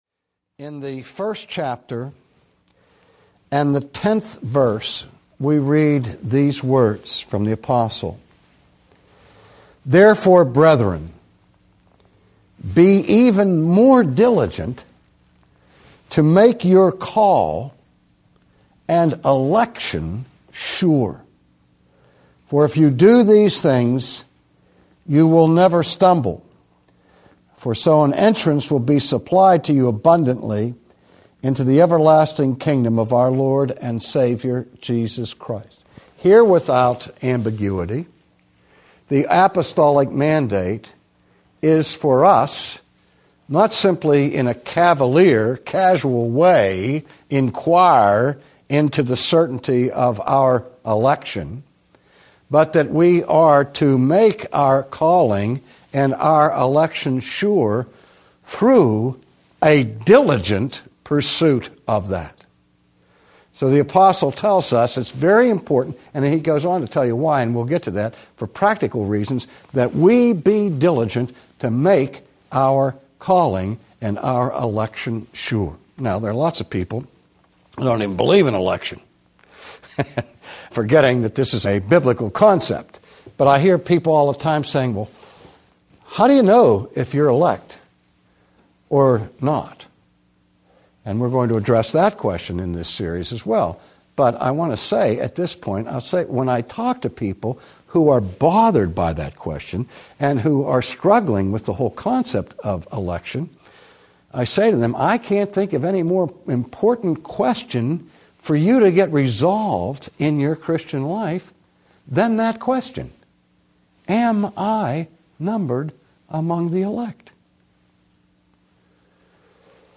The Assurance of Salvation Audiobook
Narrator
R.C. Sproul